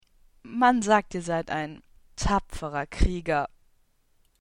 Speaker's Market - Deutsche Sprecher (f)
Selbsteinsch�tzung: sp�ttisch